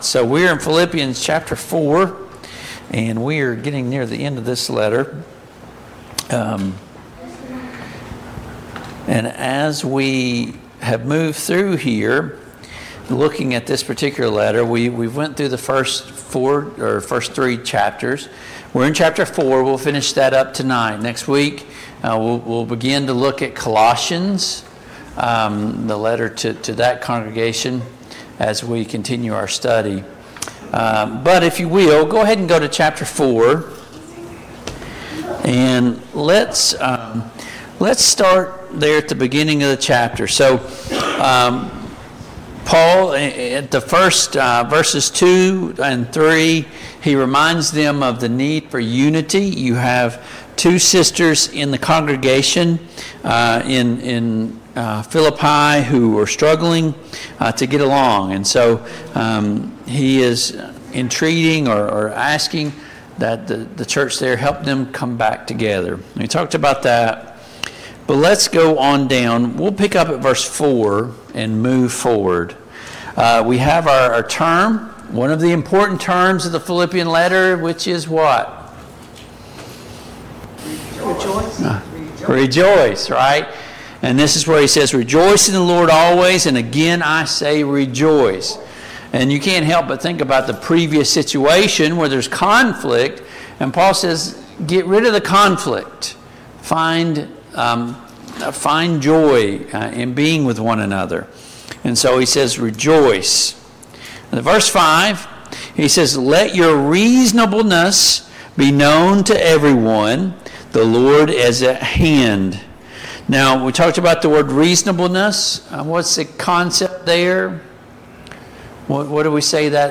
Passage: Philippians 4:4-23 Service Type: Mid-Week Bible Study